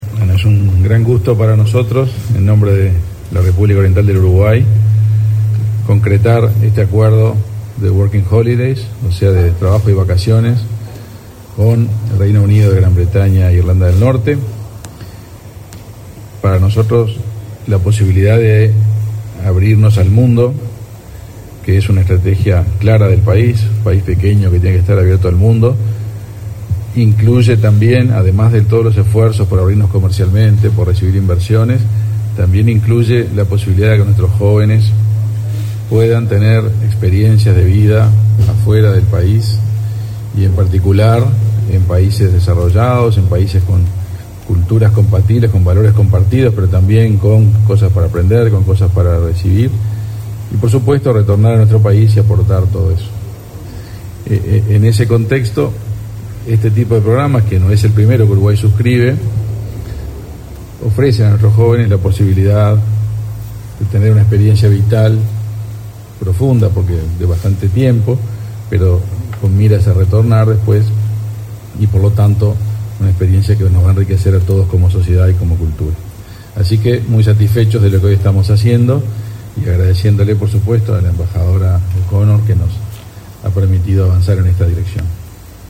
Palabras del canciller de la República, Omar Paganini
Palabras del canciller de la República, Omar Paganini 01/02/2024 Compartir Facebook X Copiar enlace WhatsApp LinkedIn Este jueves 1.° de febrero, el canciller de Uruguay, Omar Paganini, y la embajadora de Reino Unido, Faye O’Connor, se expresaron en una conferencia de prensa, con motivo del acuerdo de trabajo y vacaciones suscripto entre ambas naciones. En la oportunidad, Paganini realizó declaraciones.